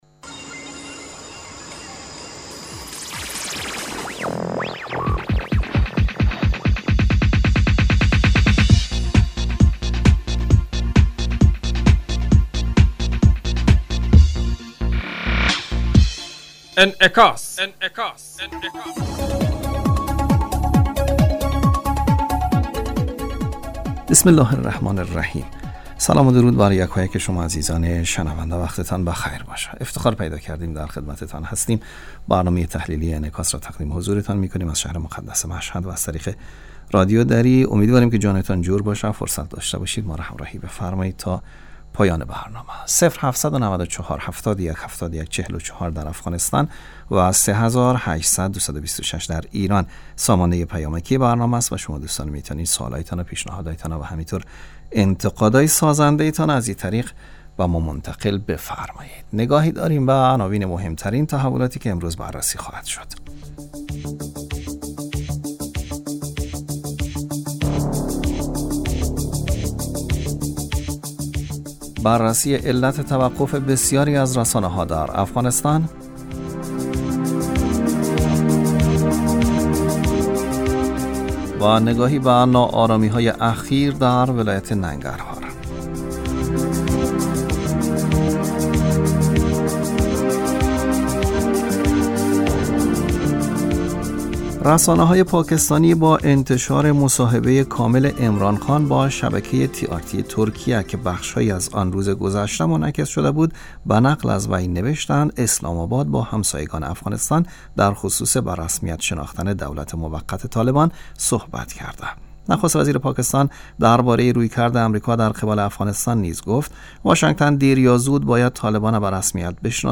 برنامه انعکاس به مدت 30 دقیقه هر روز در ساعت 12:00 ظهر (به وقت افغانستان) بصورت زنده پخش می شود.